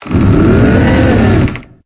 Cri de Desséliande dans Pokémon Épée et Bouclier.